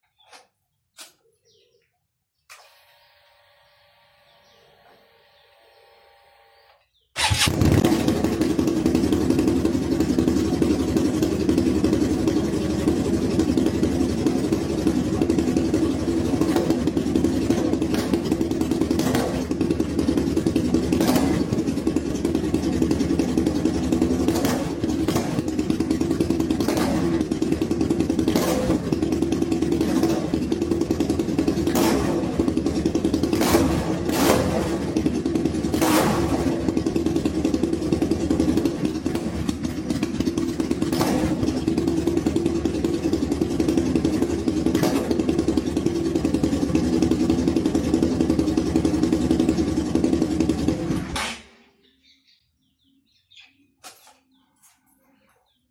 sound of napoleon 500 custom exhaust